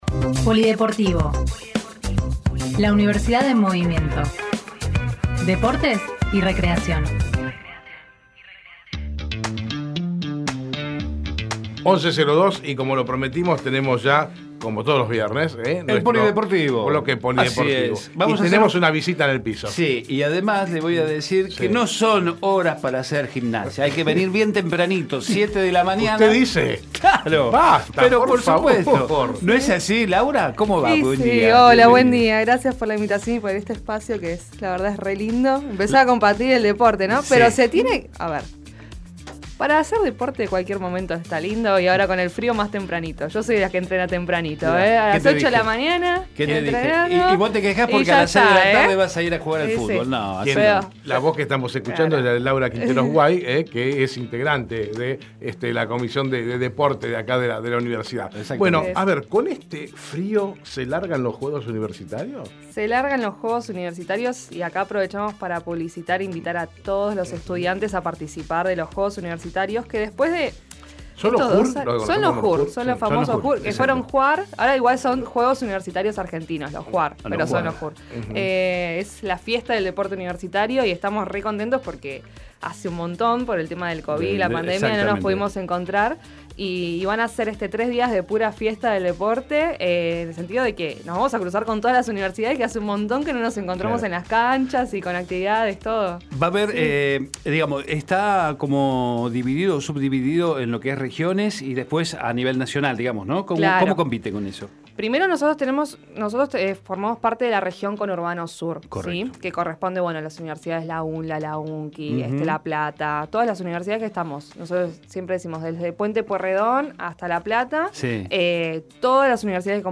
POLIDEPORTIVO todos los viernes en Hacemos PyE el espacio de los deportes y recreación de la universidad, la UNDAV en movimiento.